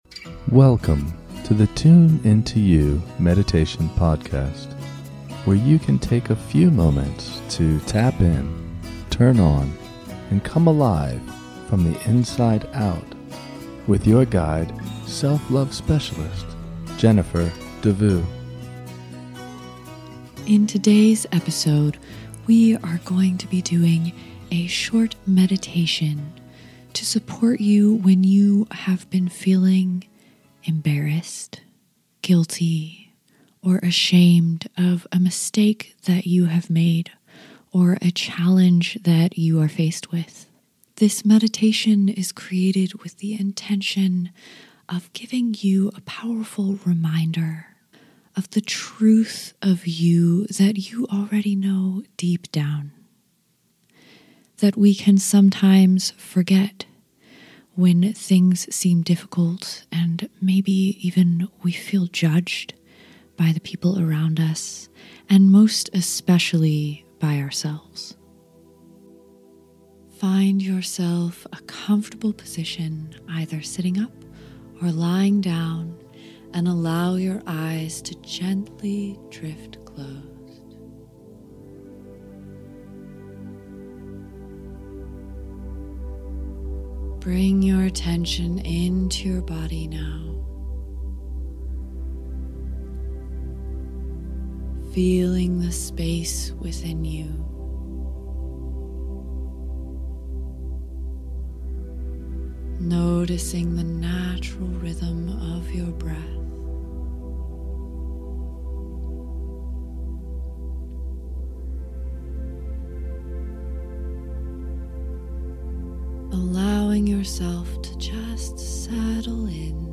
This relaxing meditation will soothe you when you feel guilty and remind you of the power still within you.
Delta wave audio by Glow Gator Sound Design.